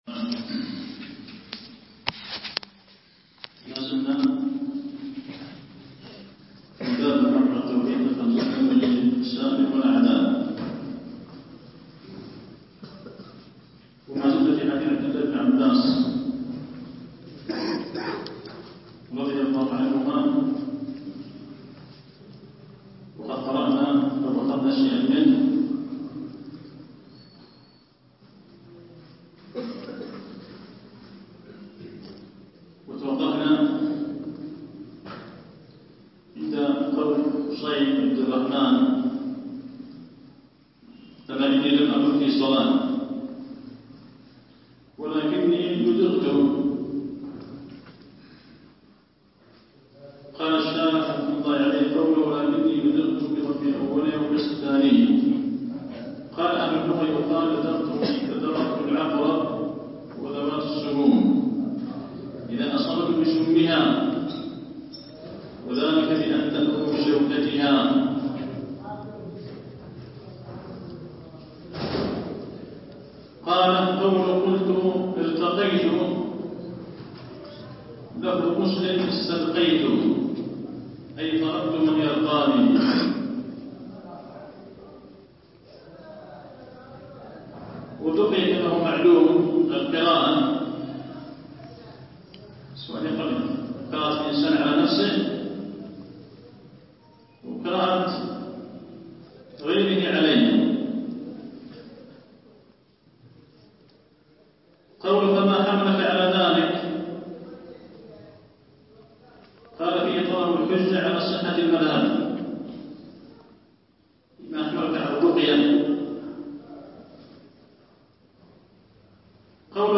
فتح المجيد الدرس ٢١.mp3